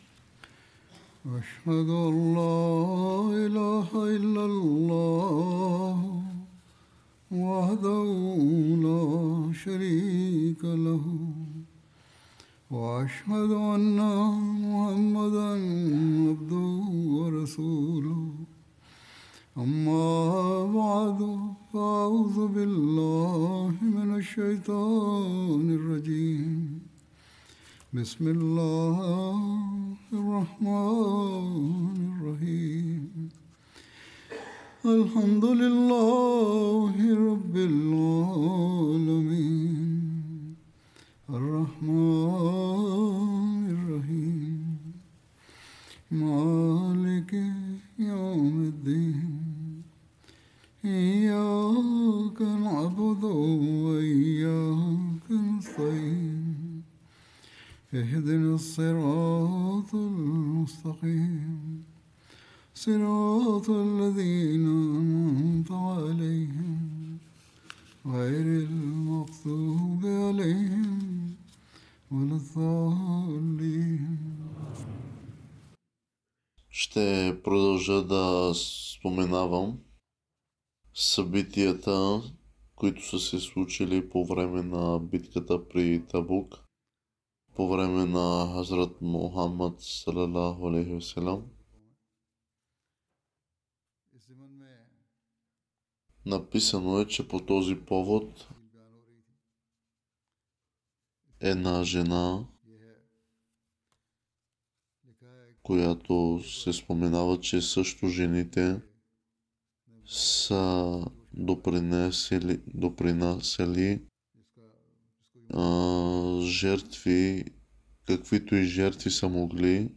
Bulgarian translation of Friday Sermon delivered by Khalifa-tul-Masih on November 14th, 2025 (audio)